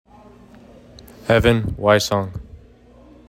Pronunciation: EHV in WHY song https